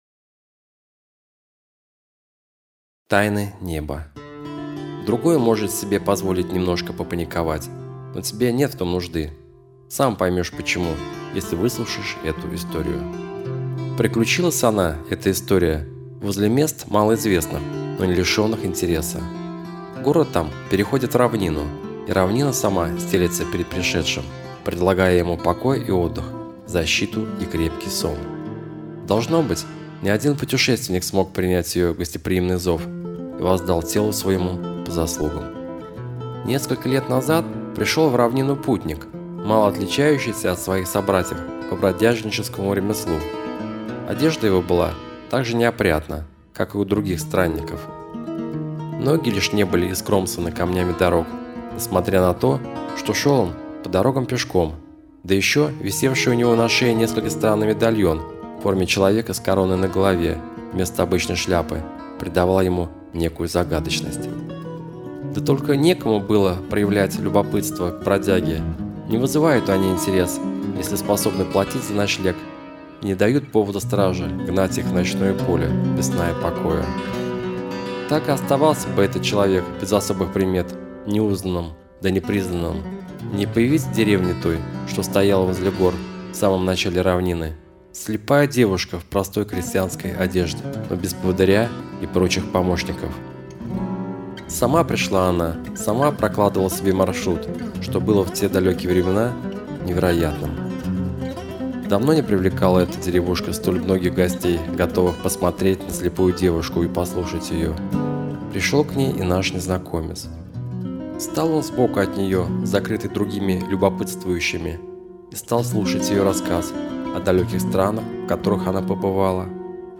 Эзотерика Ченнелинг Сказка New Age